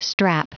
Prononciation du mot strap en anglais (fichier audio)
Prononciation du mot : strap